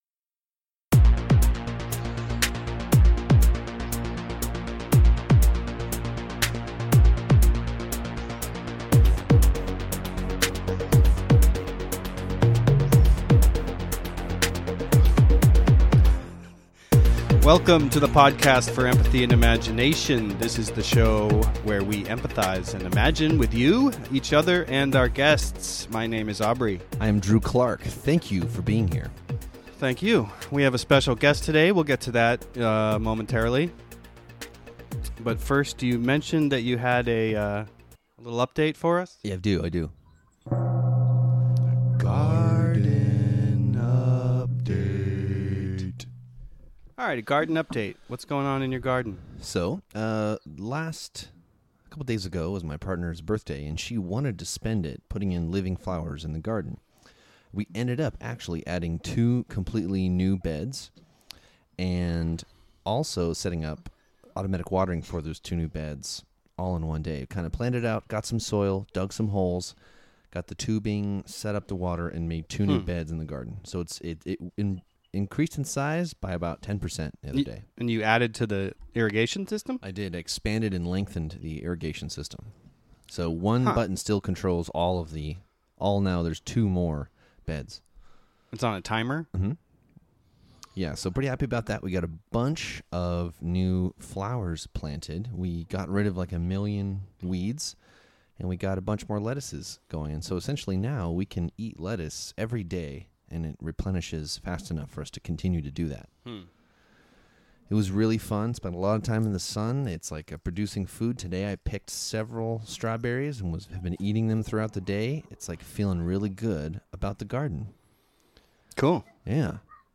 Download this episode West Coast meets East Coast this episode, thanks to the wonders of modern-day technology (an iPhone taped to a mic stand, with headphones covering the phone’s receiver).